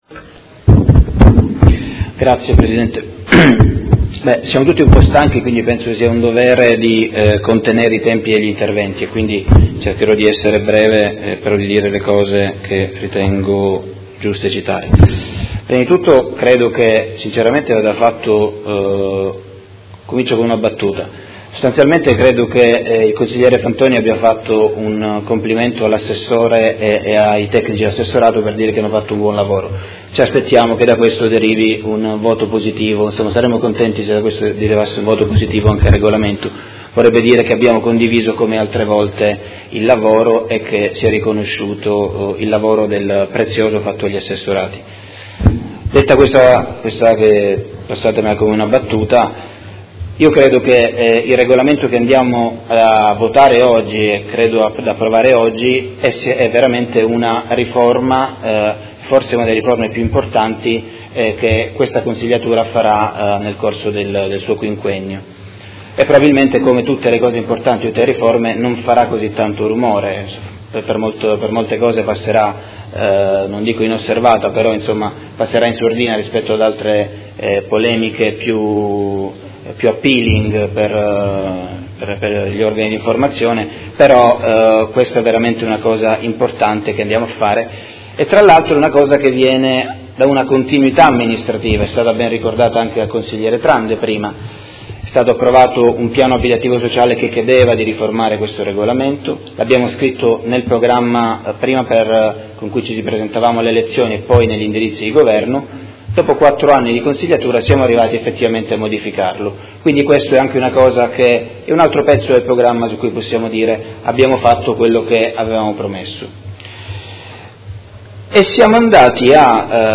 Seduta del 17/05/2018. Dibattito su proposta di deliberazione: Regolamento Edilizia convenzionata e agevolata - Approvazione, emendamenti e Ordine del Giorno presentato dal Gruppo Consigliare PD avente per oggetto: Rafforzamento delle politiche pubbliche per l'accesso alla casa attraverso il nuovo regolamento per l'edilizia convenzionata e agevolata